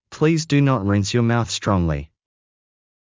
ﾌﾟﾘｰｽﾞ ﾄﾞｩ ﾉｯﾄ ﾘﾝｽ ﾕｱ ﾏｳｽ ｽﾄﾛﾝｸﾞﾘ